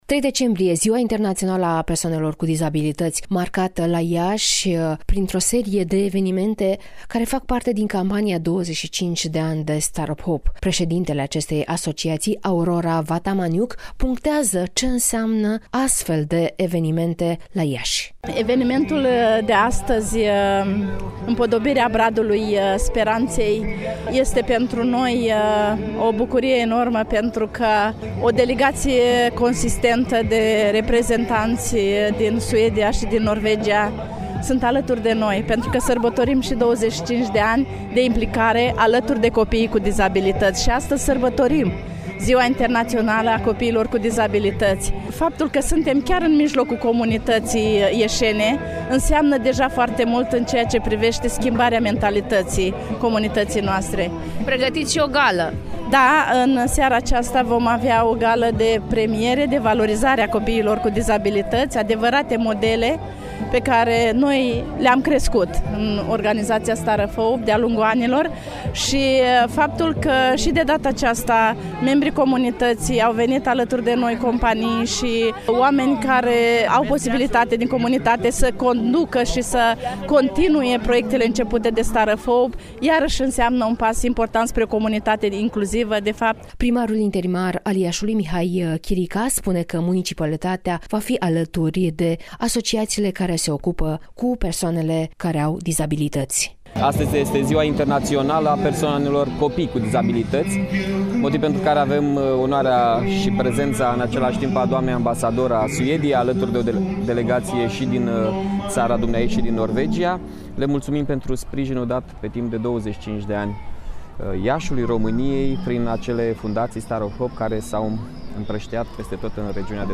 (REPORTAJ) Ziua Internațională a persoanelor cu dizabilități